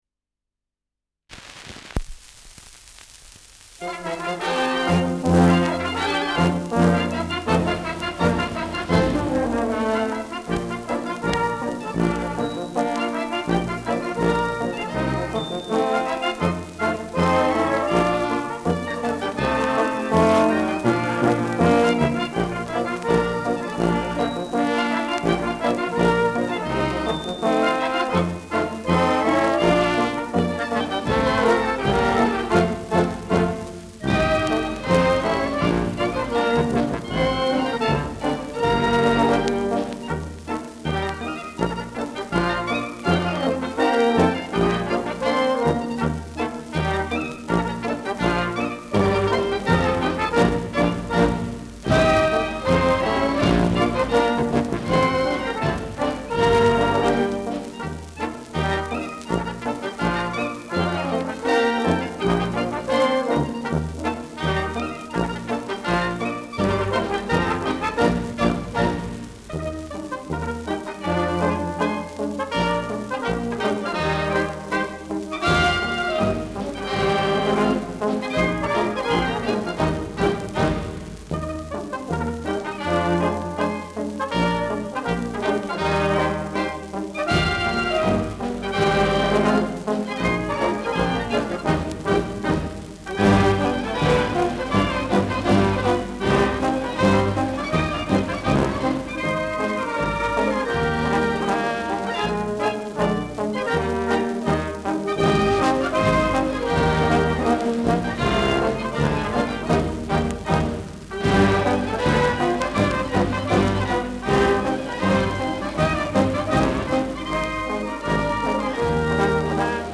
Schellackplatte
Leicht abgespielt : Gelegentliches Knacken
[Berlin] (Aufnahmeort)